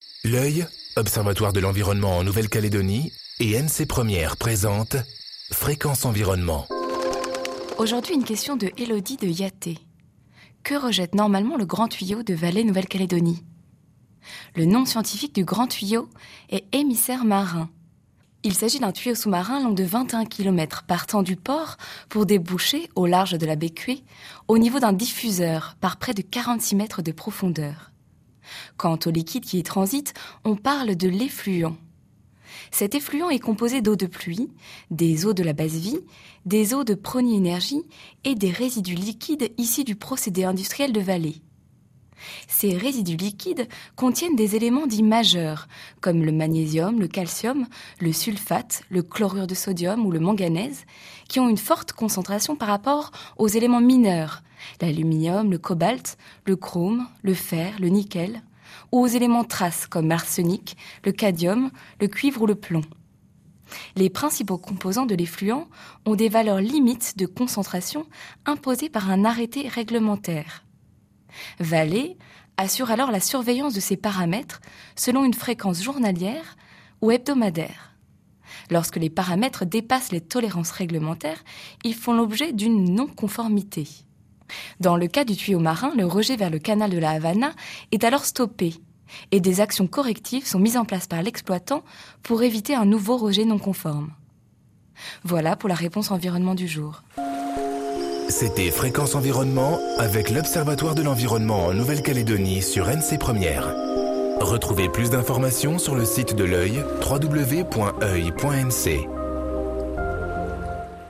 diffusée en janvier 2014 sur NC 1ère